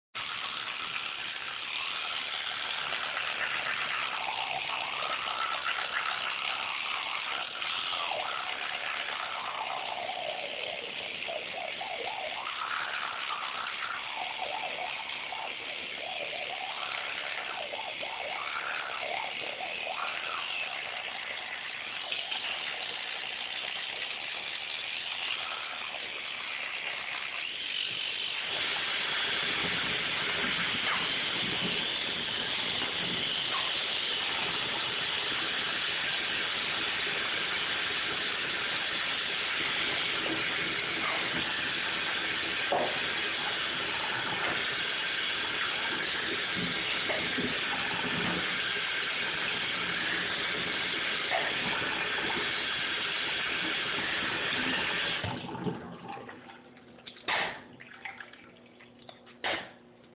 brushing – Hofstra Drama 20 – Sound for the Theatre
Field Recording
Sounds: Teeth being brushed by my suitemate, brush bristles grinding against teeth